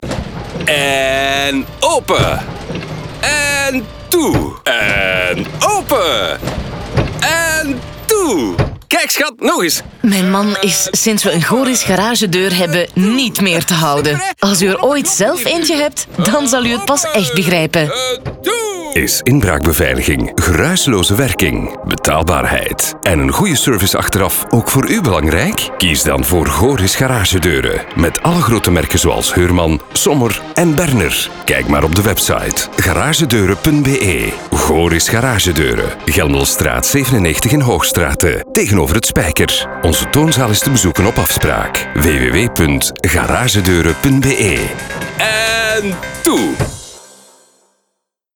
radiospot.mp3